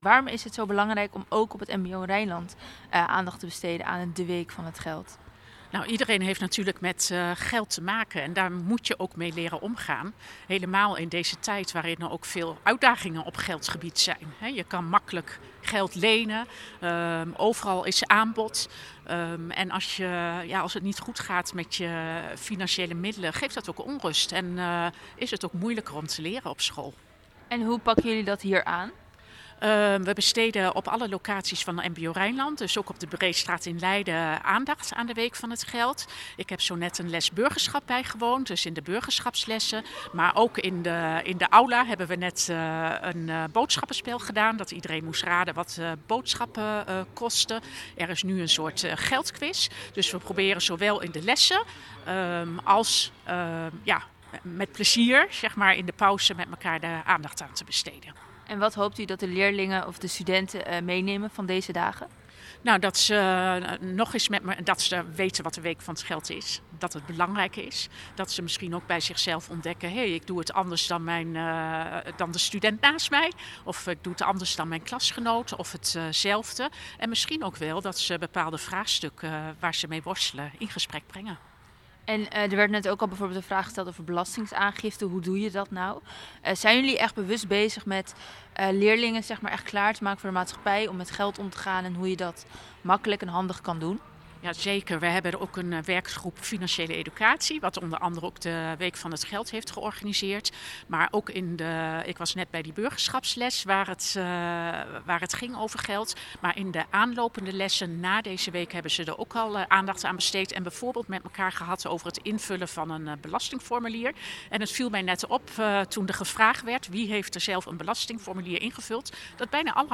in gesprek met verslaggever